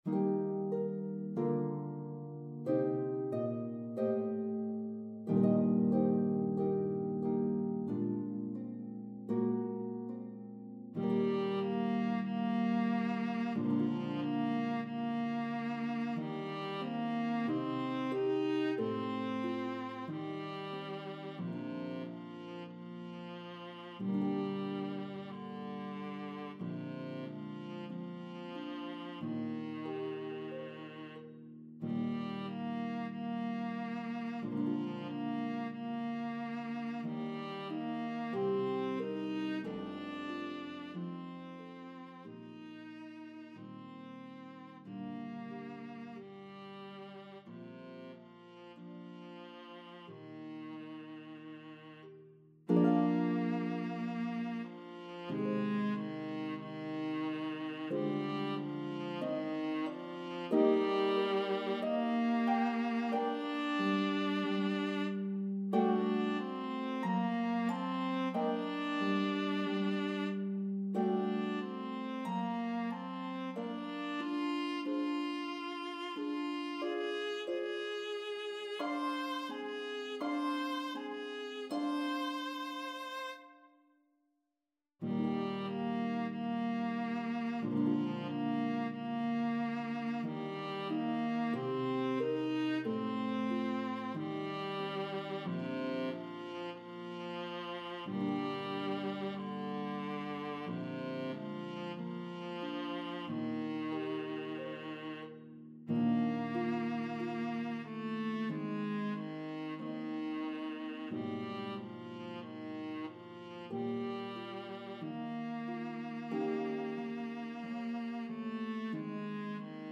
The elegant, famous melody will enchant your audiences!
Harp and Viola version